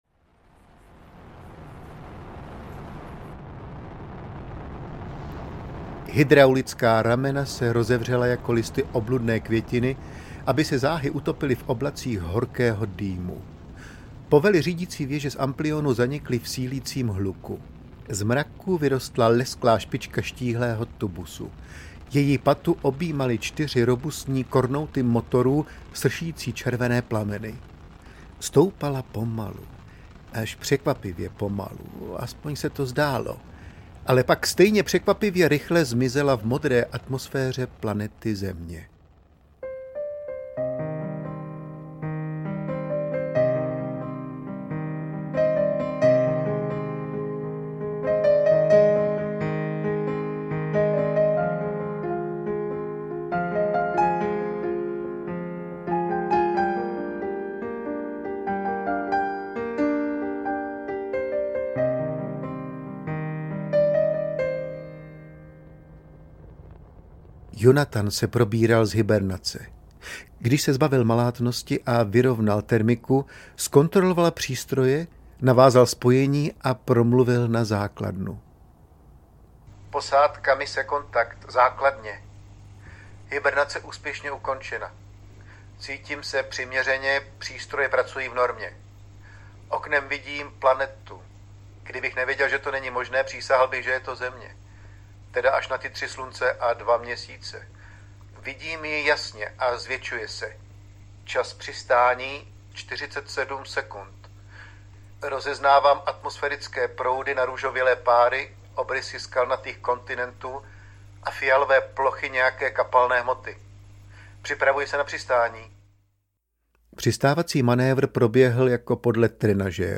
Vosa ve skafandru audiokniha
Ukázka z knihy